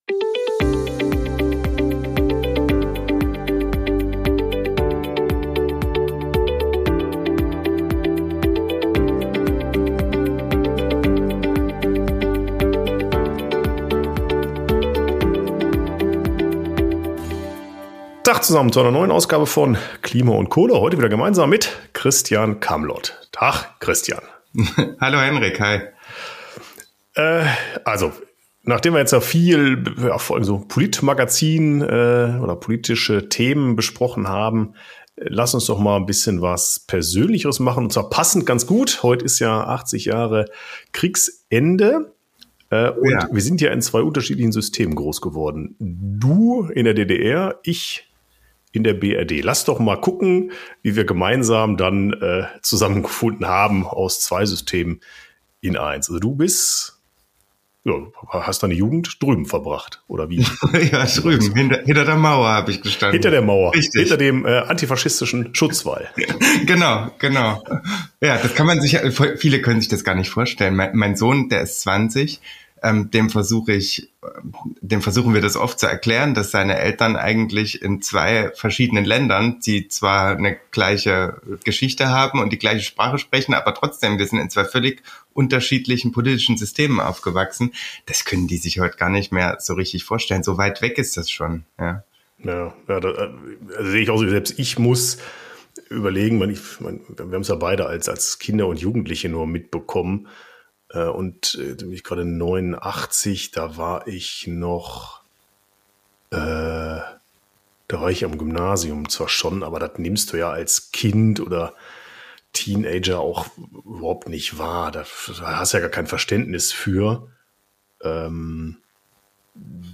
#156 Persönliche Erfahrungen in der BRD und DDR. Gespräch